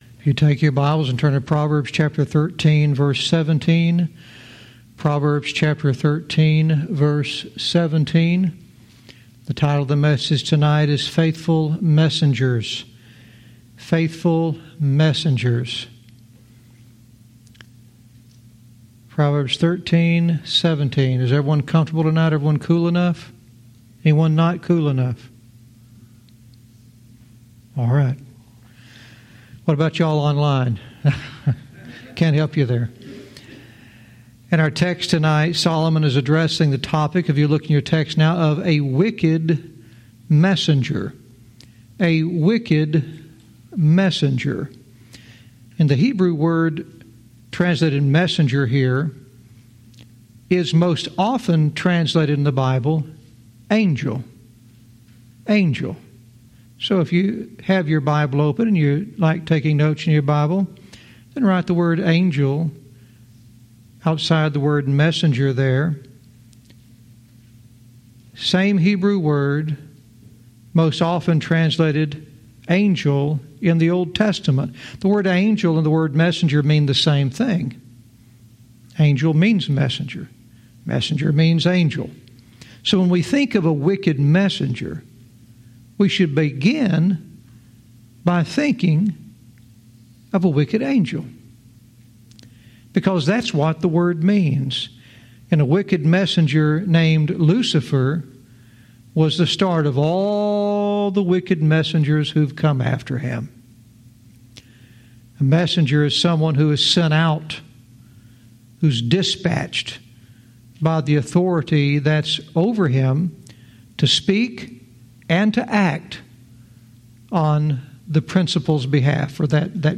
Verse by verse teaching - Proverbs 13:17 "Faithful Messengers"